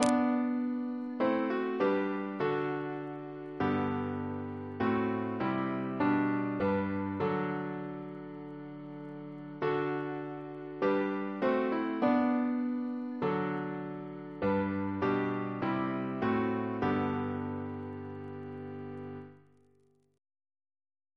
Double chant in G Composer: William Crotch (1775-1847), First Principal of the Royal Academy of Music Reference psalters: ACB: 380; ACP: 235